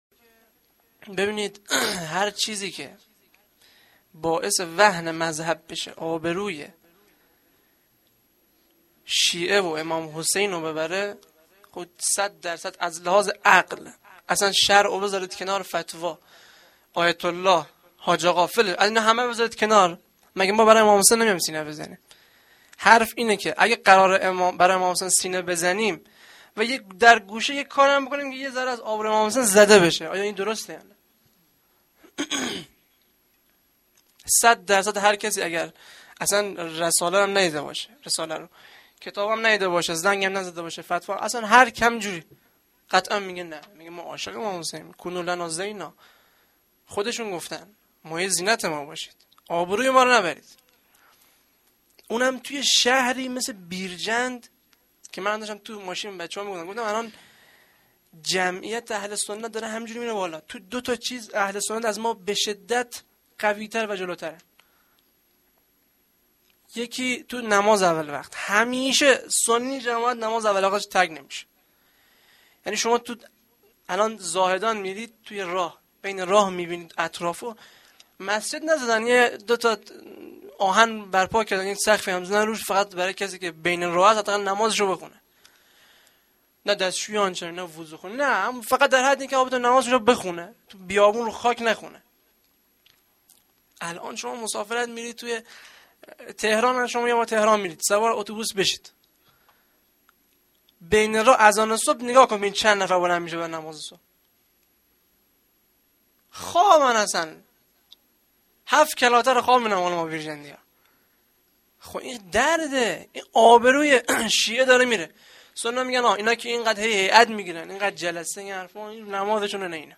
sokhanrani(Rozatol Abbas.Haftegi93.04.11).mp3